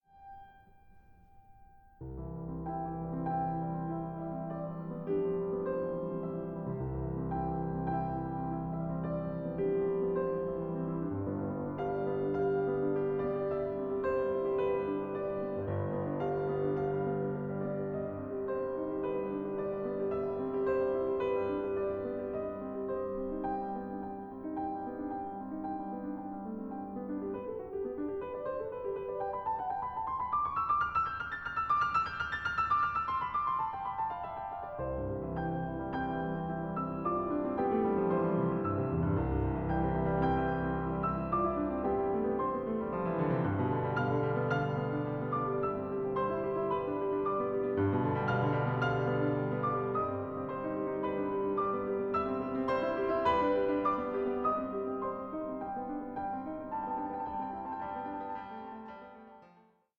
Piano Sonata No. 21 in C, Op. 53